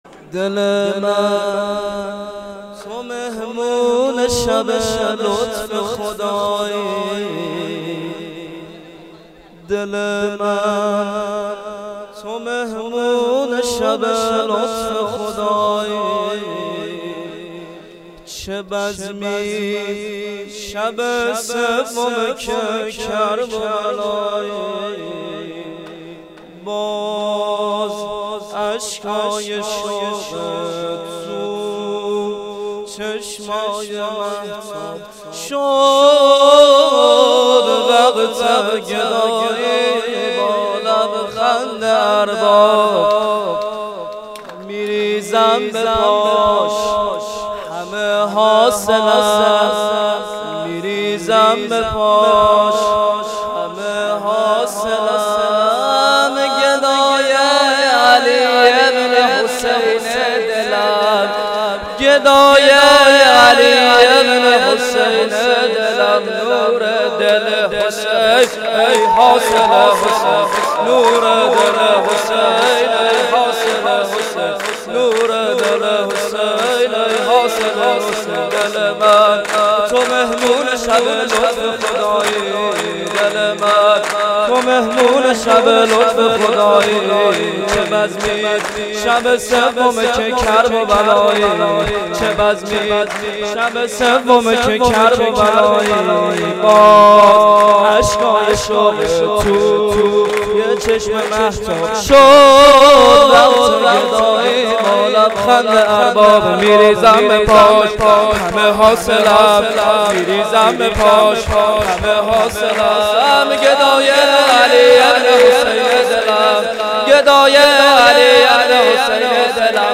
خیمه گاه - هیئت رایة المهدی (عج) قم - سرود | دل من تو مهمون شب لطف خدایی